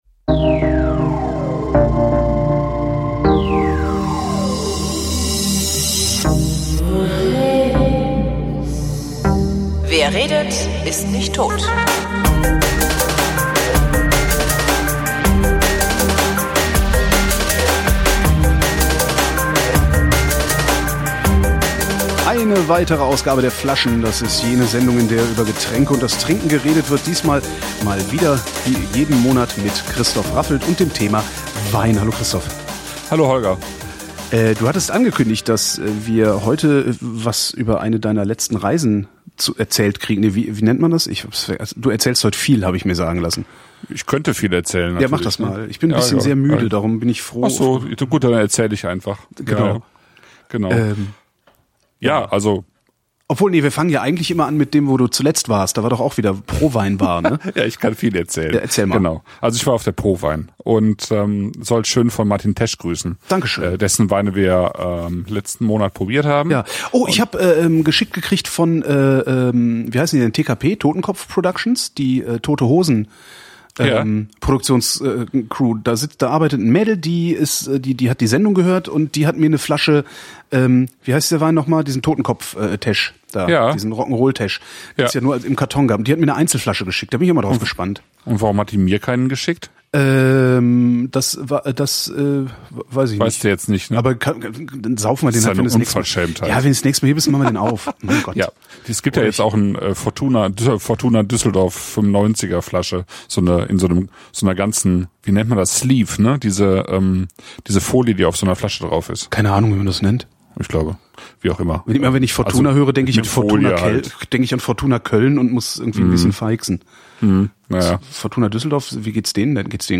Gegen Ende der Sendung kann man gut hören, was das für Folgen hat.